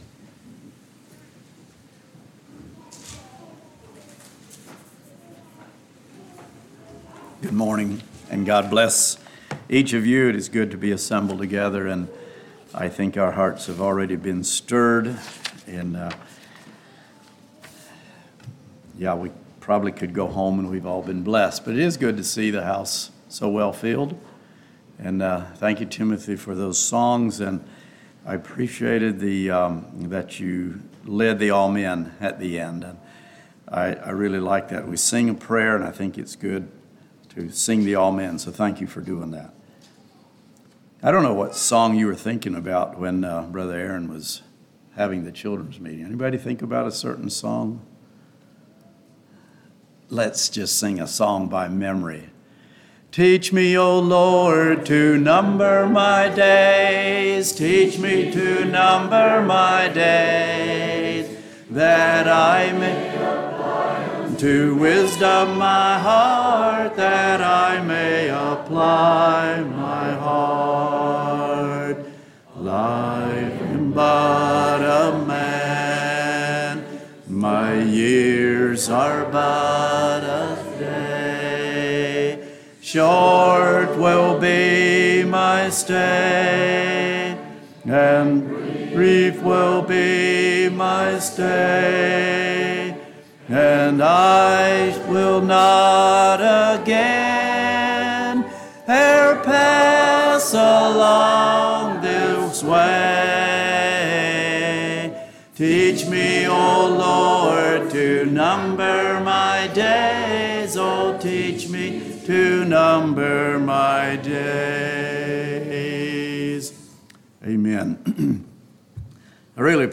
Sermons
Smithdale | Bible Conference 2025